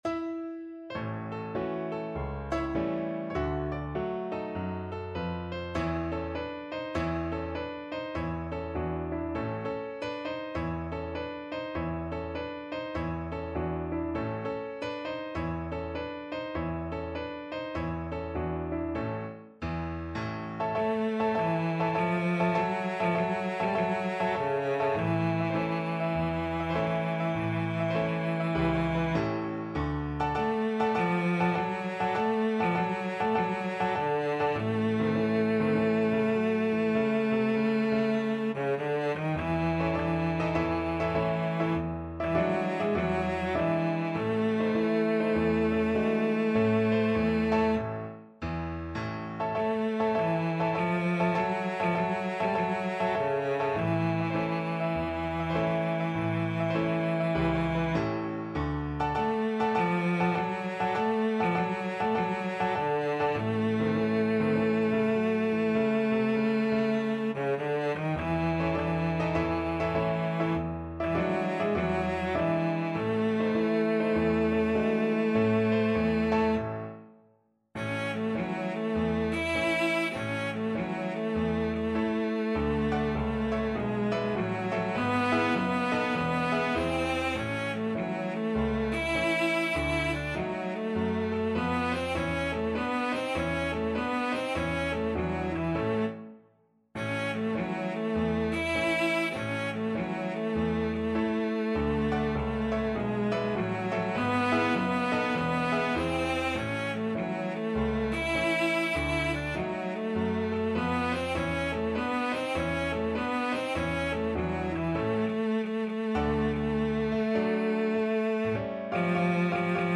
Jazz Jazz Cello
4/4 (View more 4/4 Music)
Jazz (View more Jazz Cello Music)
Rock and pop (View more Rock and pop Cello Music)